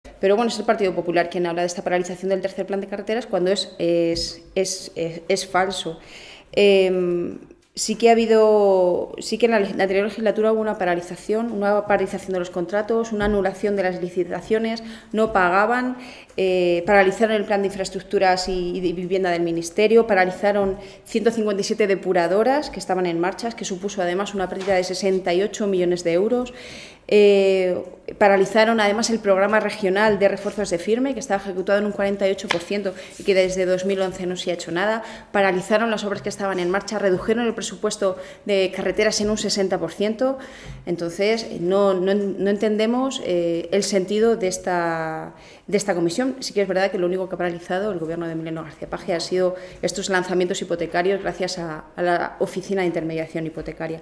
La diputada del Grupo Parlamentario Socialista en las Cortes de Castilla-La Mancha, Rosario García, ha destacado la buena marcha de las obras en carreteras que se está llevando a cabo por parte del gobierno del presidente García-Page.
Cortes de audio de la rueda de prensa